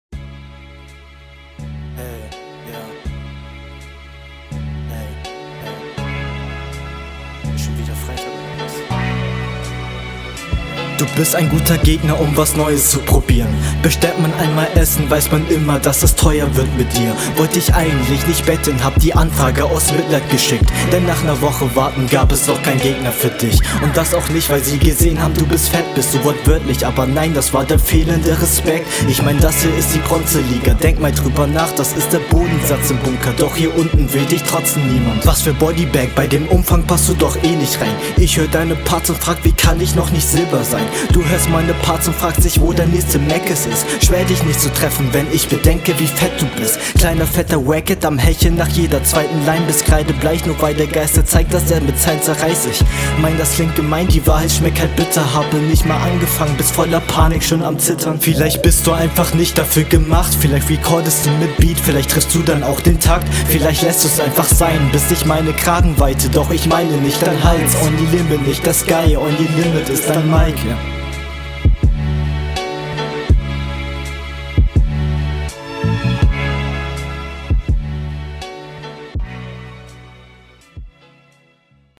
Chilliger flow, interessante Ansätze bei punches.
Flow: schön Staccato.
schöner beat, sehr smooth alles und lustige lines